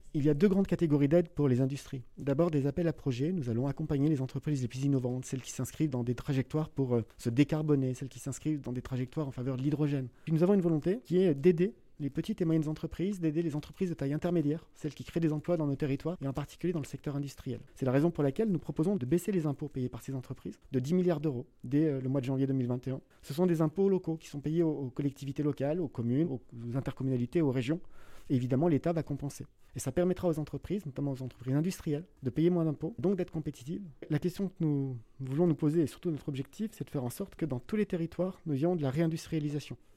Au micro de Chérie FM Vallée du Rhône, il a présenté ce projet de loi.